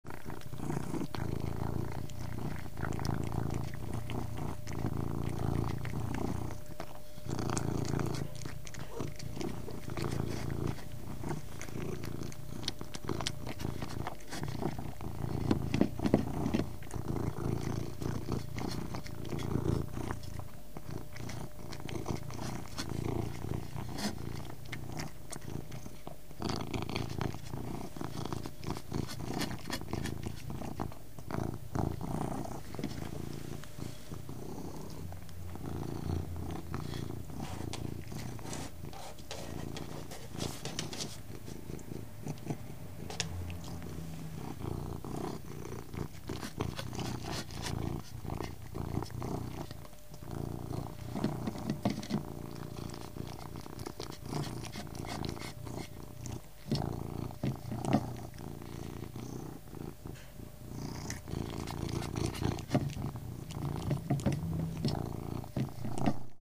Звуки котов
Кошка лакомится сметаной и мурлычет